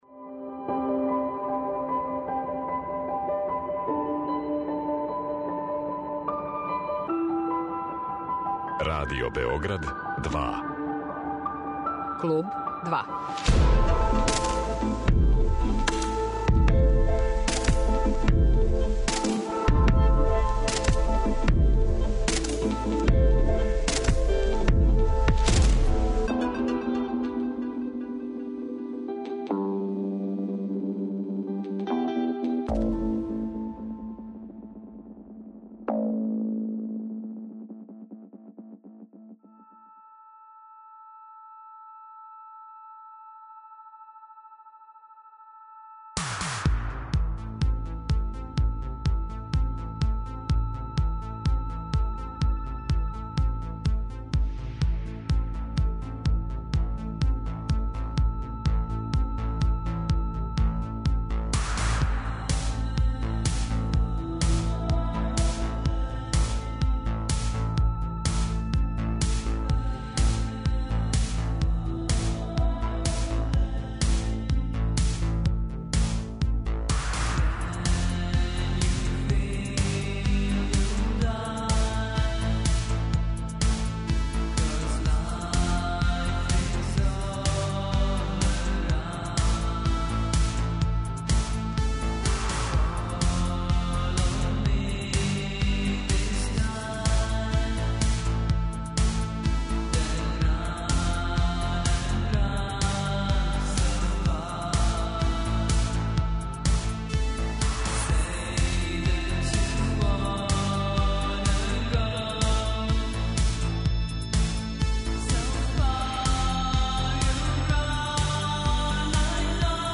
Овог петка, 'Клуб 2' уживо са Егзит фестивала у Новом Саду.